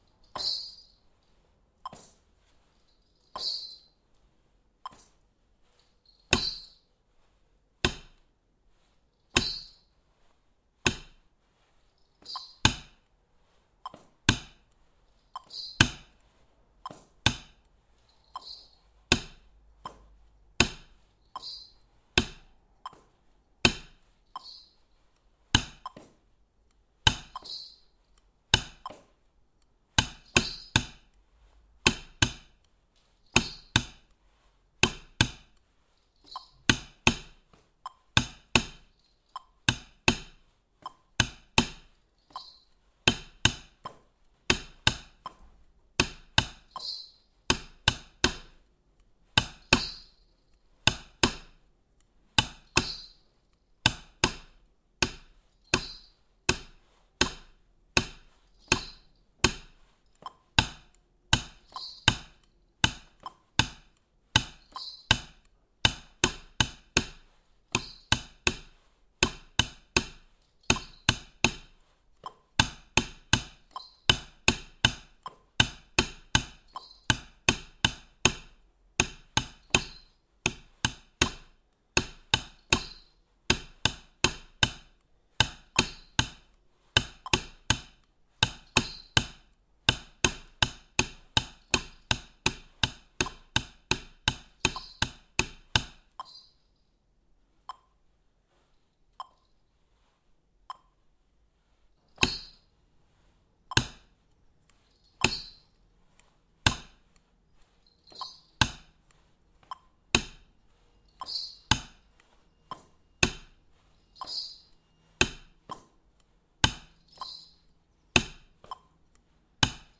Am Anfang sind vier Einzähler vom Metronom zu hören, was die ganze Zeit durchläuft. In einer Minute 40 Takte.
Da drei statt vier Schläge ab Buchstabe Q auf einen Takt kommen, wirkt das Tempo langsamer.
Daher nochmal die Dreier-Reihe etwas schneller.
ABC-noten-160BPM.mp3